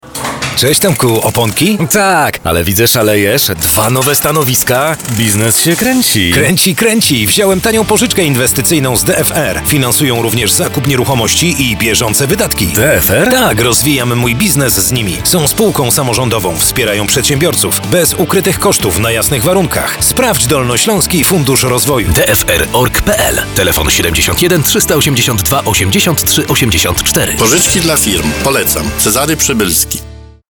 30-sekundowy spot reklamowy będzie się ukazywał do końca maja w Radiu Wrocław, Radiu Złote Przeboje i  w stacji Radio Gra (Grupa RMF).
W reklamie dwaj przedsiębiorcy z sektora MŚP rozmawiają o swoich biznesowych planach i możliwościach ich finansowania.